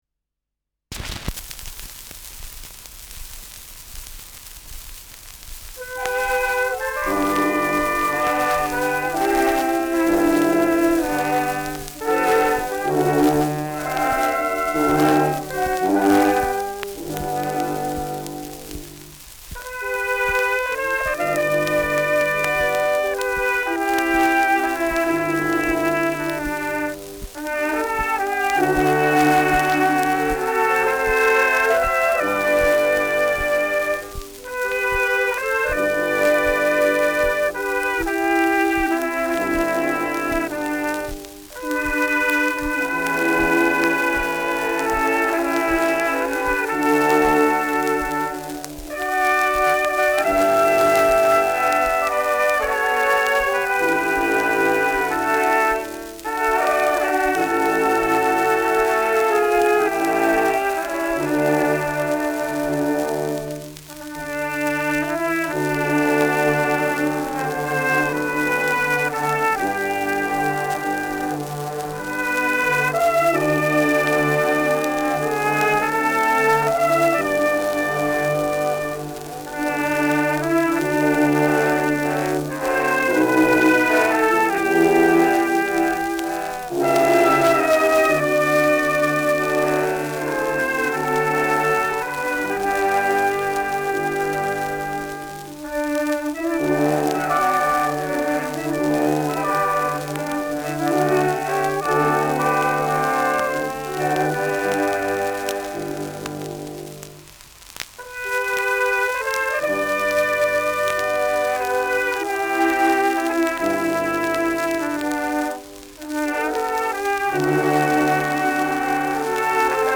Schellackplatte
Abgespielt : Teils leicht verzerrt : Gelegentlich leichtes Knacken
Stadtkapelle Fürth (Interpretation)
[Berlin] (Aufnahmeort)
Schützenkapelle* FVS-00006